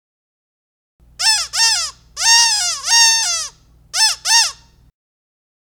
Squeaker Mouth Double-Voice - Medium (12 Pack) - Trick
Double-voice squeakers make a sound both when they are pressed and when they are released.